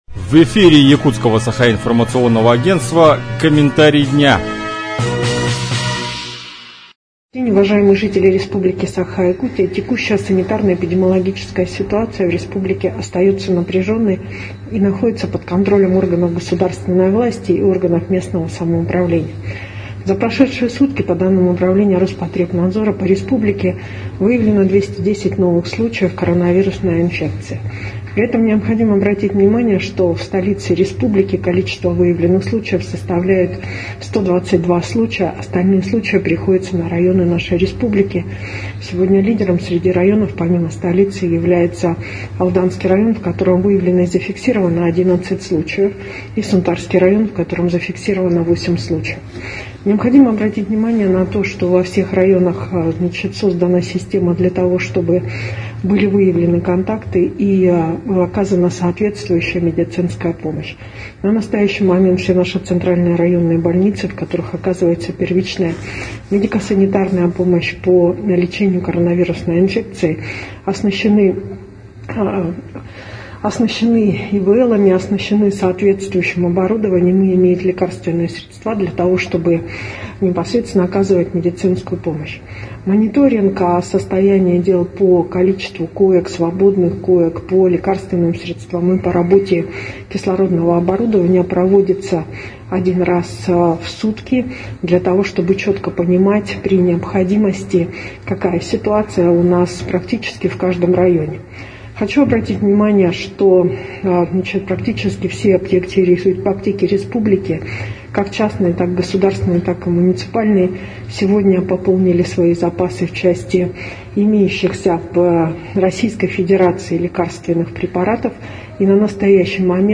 Про обстановку в Якутии на 11 ноября рассказала вице-премьер Якутии Ольга Балабкина.